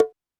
Drums_K4(25).wav